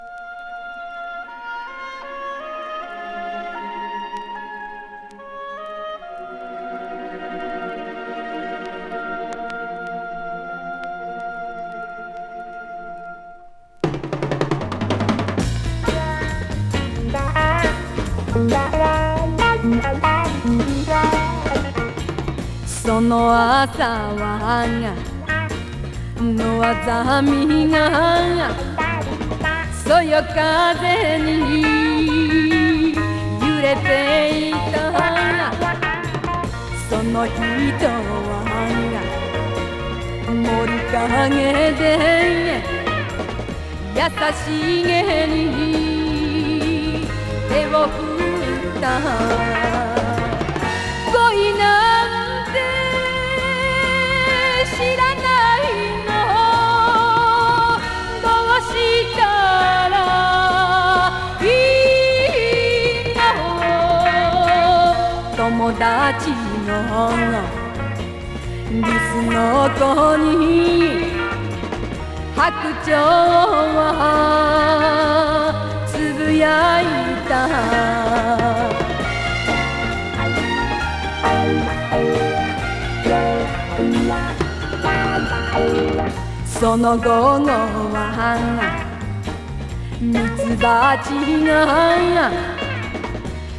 情熱的なヴォーカルと、ファンキーな演奏が見事にフィットしてます。